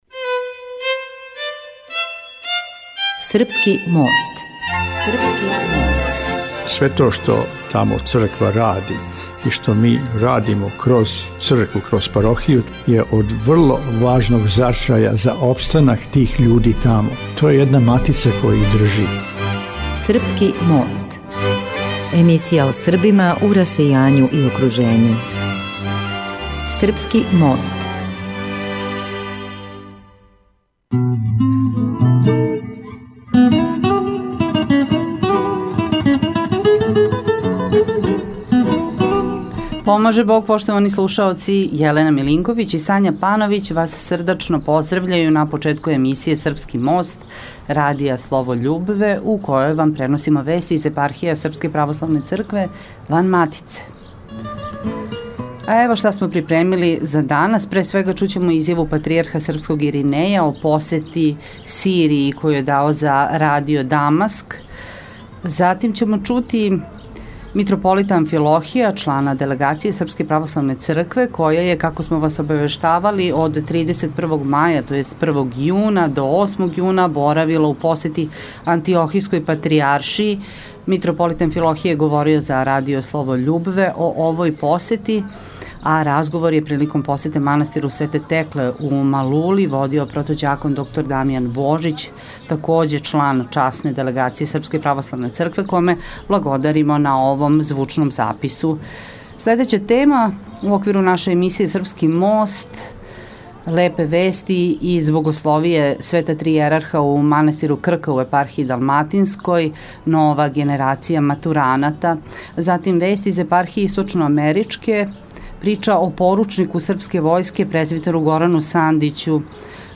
Изјава Патријарха српског Иринеја о посети Сирији за Радио „Дамаск“. Митрополит Амфилохије, члан делегације СПЦ која је недавно боравила у посети Антиохијској Патријаршији, говорио је за Радио „Слово љубве“ о овој посети.